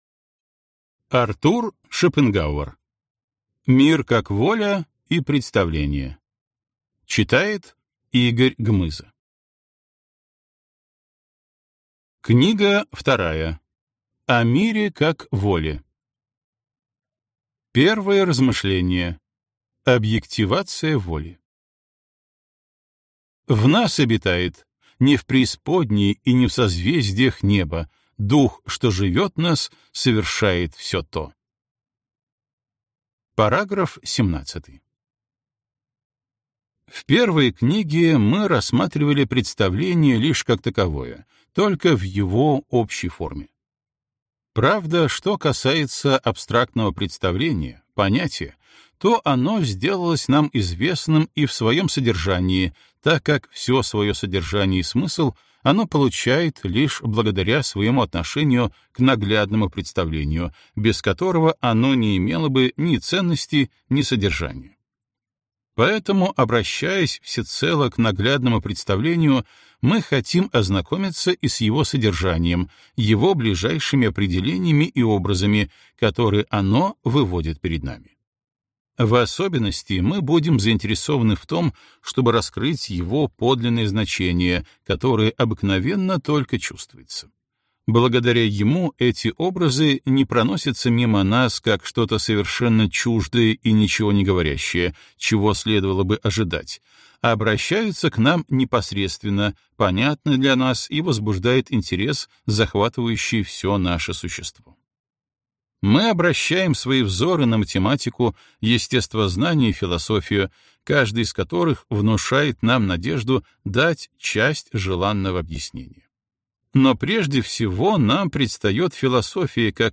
Аудиокнига Книга 2. О мире как воле | Библиотека аудиокниг